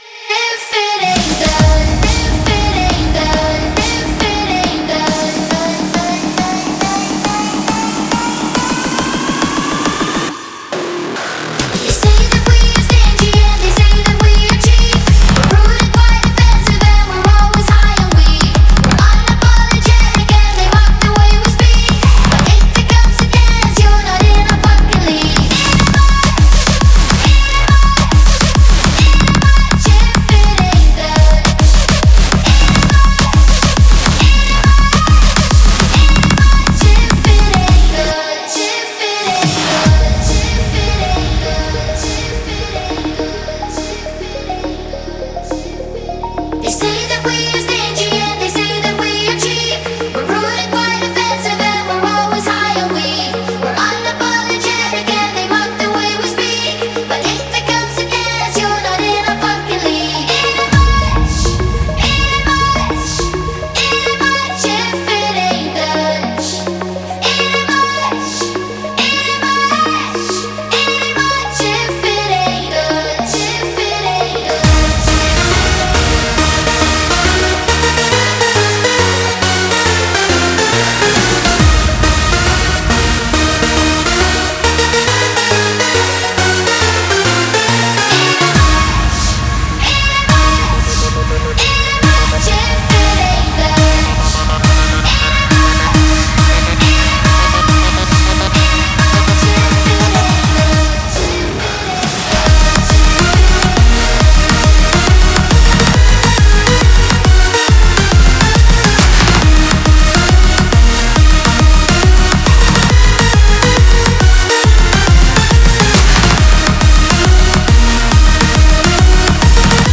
Клубная